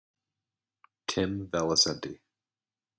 Hear name pronounced.